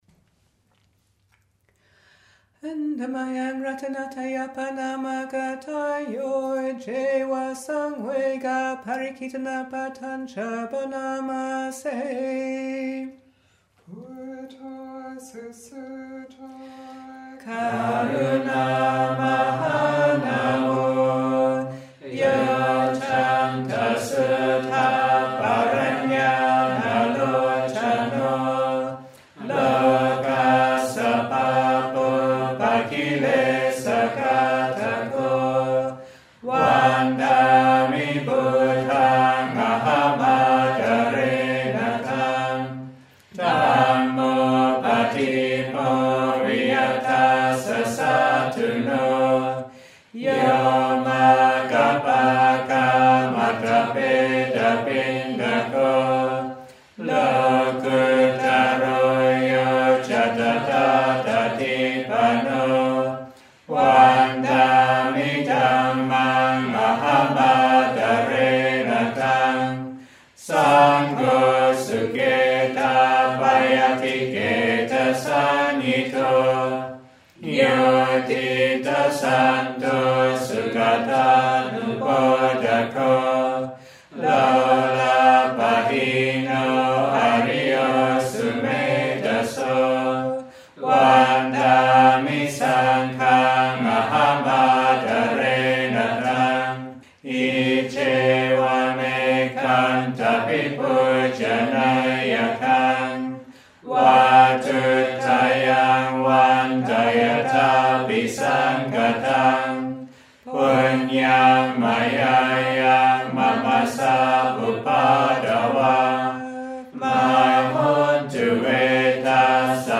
» Pali-English Chanting